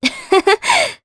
Aselica-Vox-Laugh_jp.wav